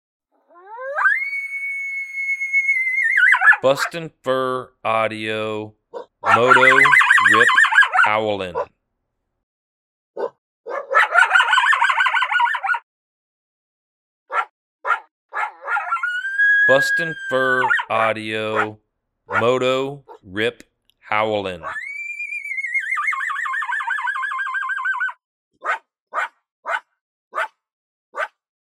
2 Year old Male Coyote getting fired up in this long howl series.